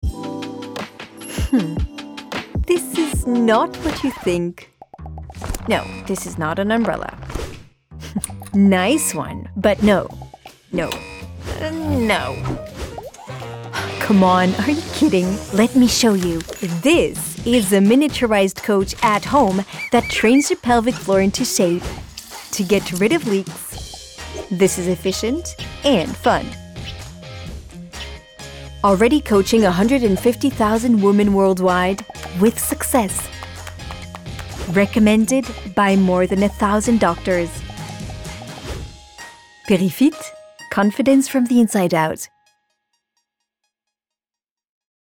PUBLICITE VOIX AMUSÉE (ENG)
Une comédienne voix off professionnelle pour des voix jouées et chantées